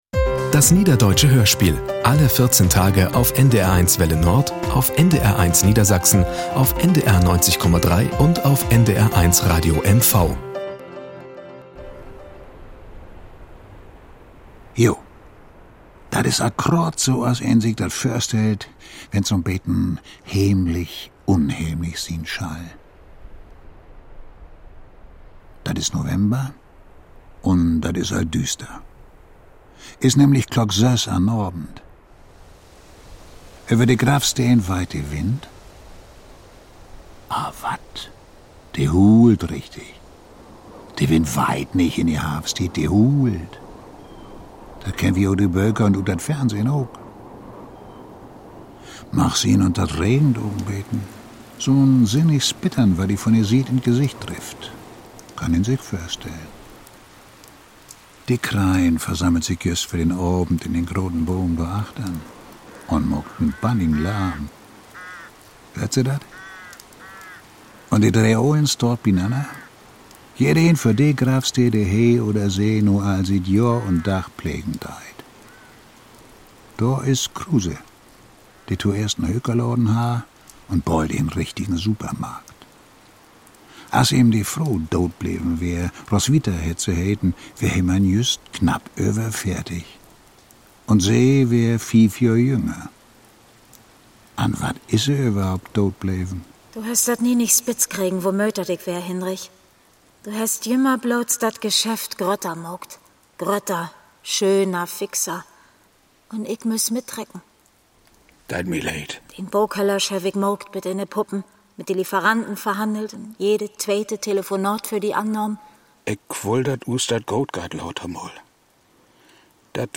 Jochen Schimmang: So oder anners ~ Das Niederdeutsche Hörspiel Podcast